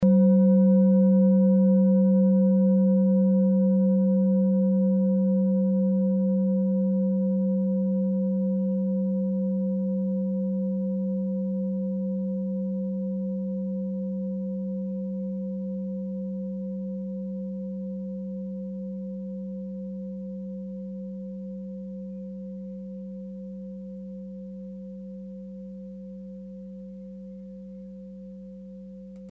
Klangschale Orissa Nr.16
Die Klangschale kommt aus einer Schmiede in Orissa (Nordindien). Sie ist neu und wurde gezielt nach altem 7-Metalle-Rezept in Handarbeit gezogen und gehämmert.
(Ermittelt mit dem Filzklöppel oder Gummikernschlegel)
klangschale-orissa-16.mp3